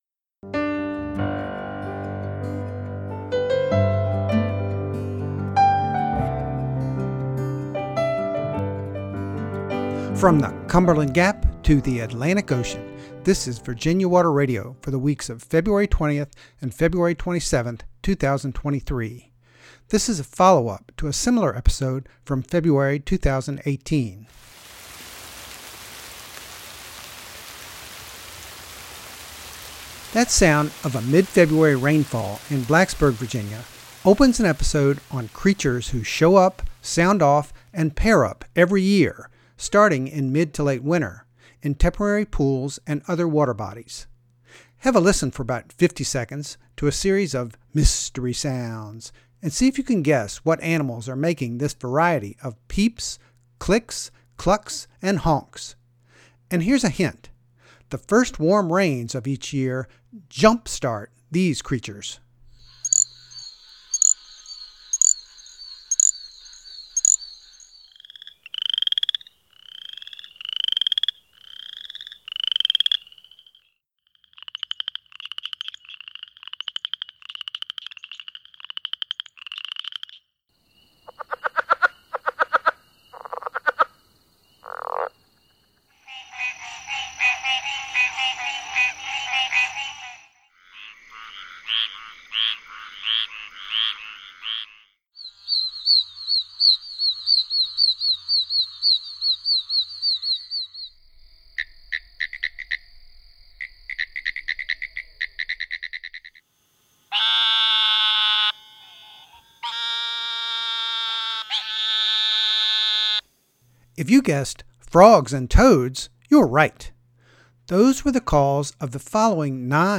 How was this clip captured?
The rainfall sound was recorded by Virginia Water Radio in Blacksburg on February 17, 2023.